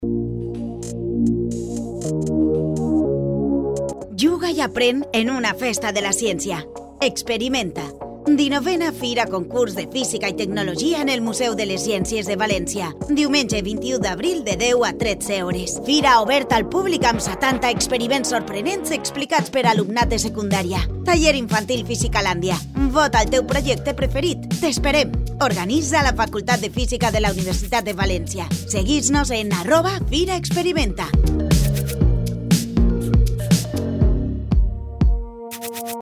Falca de radio